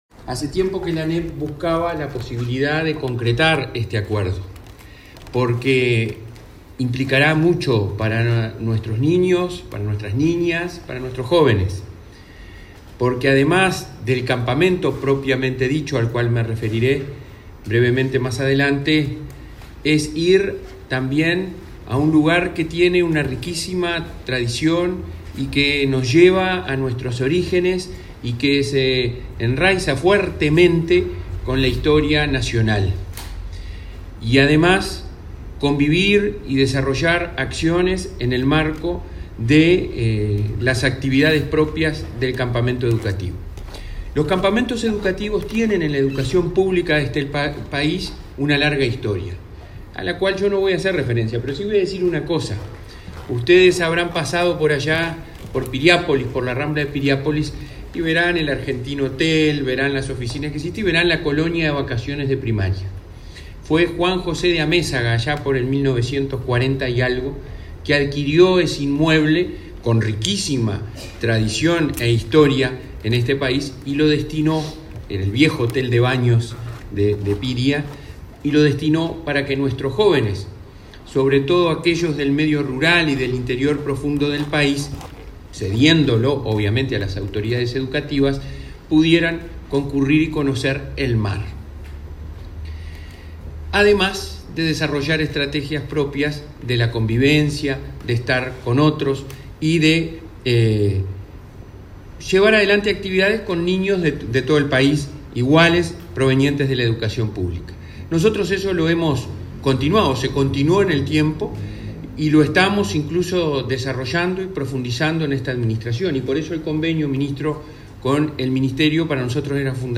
Palabras de autoridades en convenio entre ANEP y Ministerio de Defensa Nacional
La Administración Nacional de Educación Pública (ANEP) y el Ministerio de Defensa Nacional (MDN) suscribieron un convenio con el fin de habilitar el uso de las instalaciones del Parque Nacional de Santa Teresa para campamentos educativos. El presidente de la ANEP, Robert Silva, y el titular de la citada cartera, Javier García, señalaron la importancia de esta colaboración.